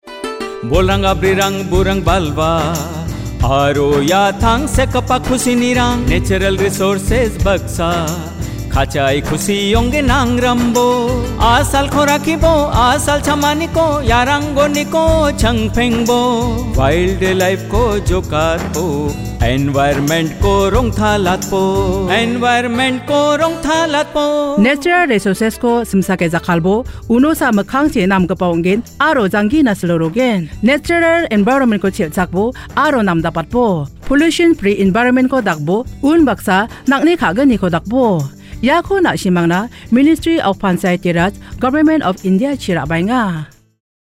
53 Fundamental Duty 7th Fundamental Duty Preserve natural environment Radio Jingle Garo